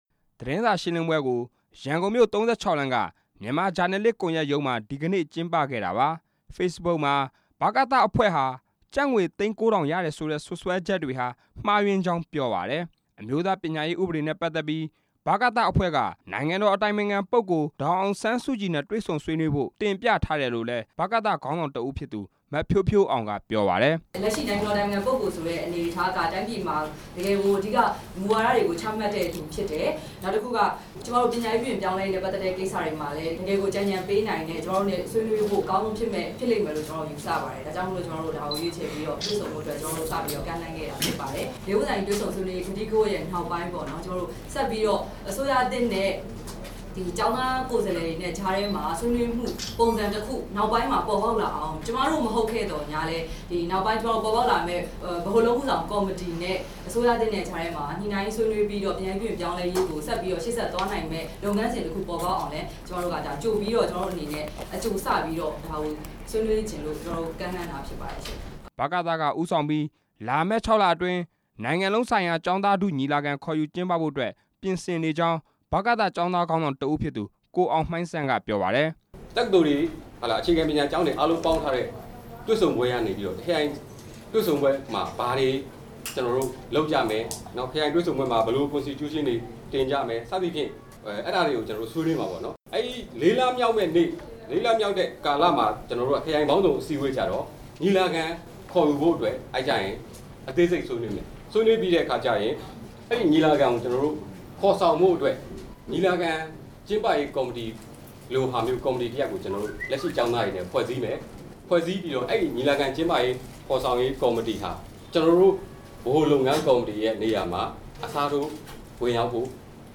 ဗမာနိုင်ငံလုံးဆိုင်ရာ ကျောင်းသားသမဂ္ဂများအဖွဲ့ချုပ်ဗကသအဖွဲ့က ဘဏ္ဍာရေးကိစ္စ၊ နိုင်ငံလုံးဆိုင်ရာ ကျောင်းသားထုညီလာခံ ခေါ်ယူနိုင်ရေးကိစ္စအပြင် နိုင်ငံတော်အတိုင်ပင်ခံပုဂ္ဂိုလ် ဒေါ်အောင်ဆန်းစုကြည်နဲ့ တွေဆုံဖို့ တင်ပြထားတဲ့အကြောင်းတွေကို ဒီနေ့ သတင်းစာရှင်းလင်းပွဲမှာ ရှင်းပြခဲ့ပါတယ်။